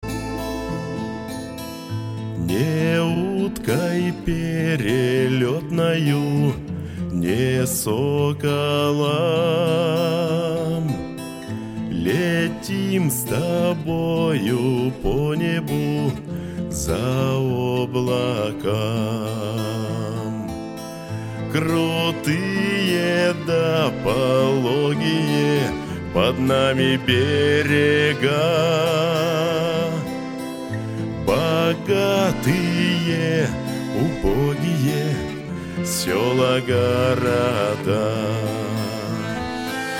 фолк